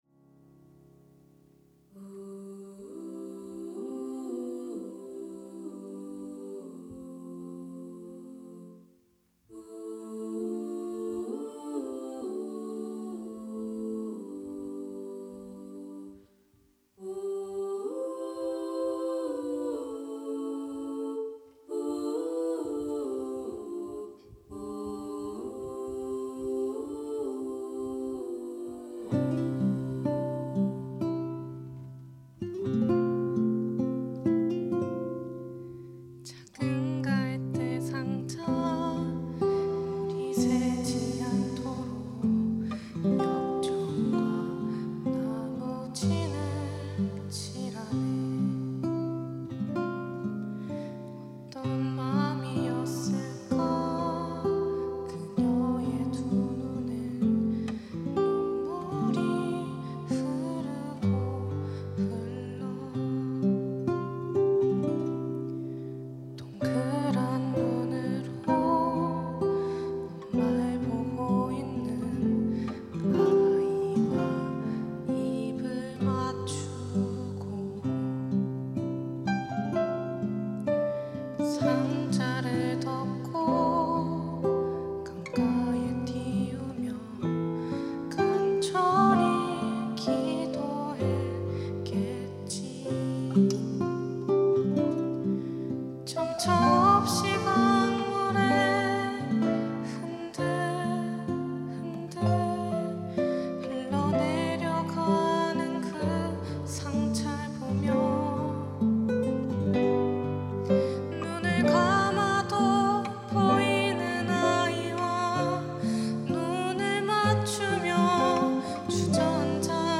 특송과 특주 - 요게벳의 노래
청년부